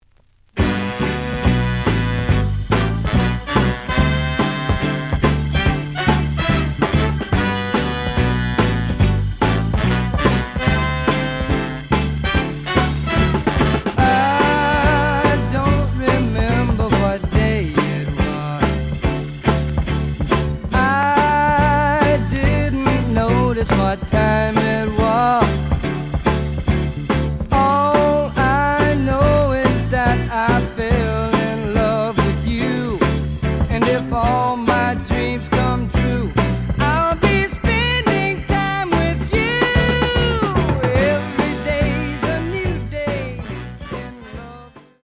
The audio sample is recorded from the actual item.
●Genre: Soul, 60's Soul
Some click noise on middle of A side.